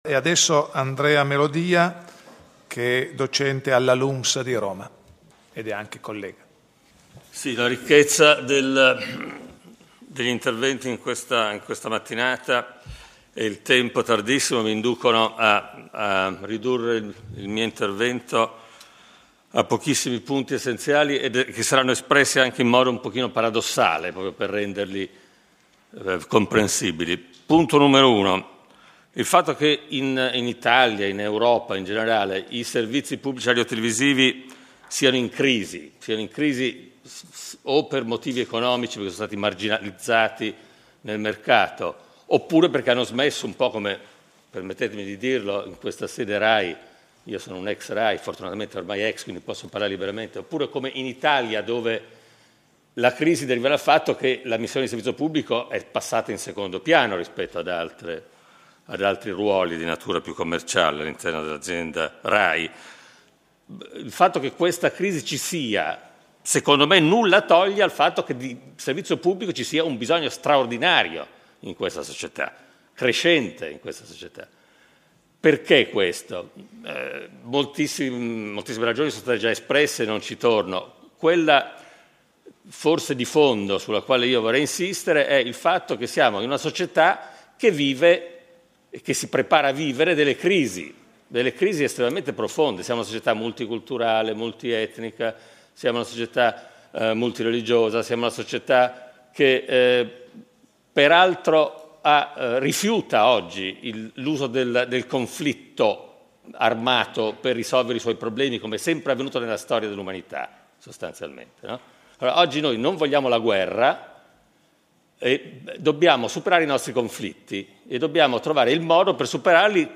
Turin, Rai - Prix Italia – 24 settembre 2009
DÉBATE